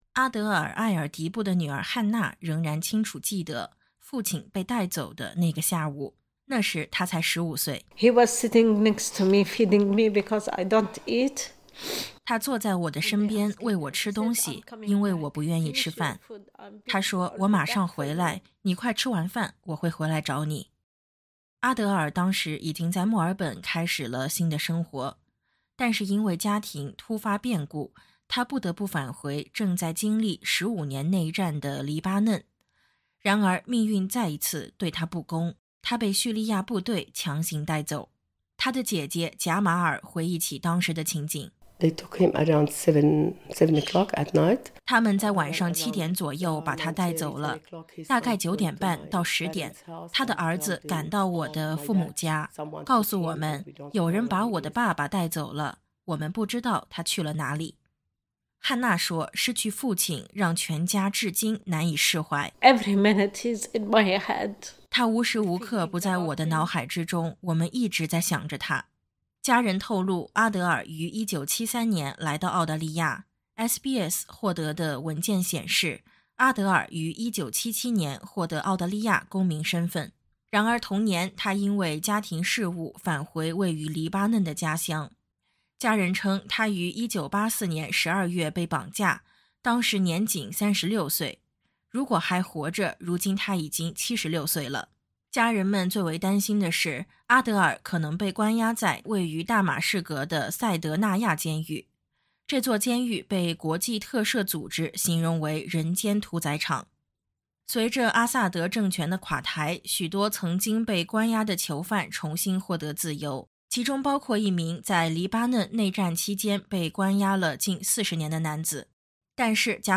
点击音频，收听综合报道。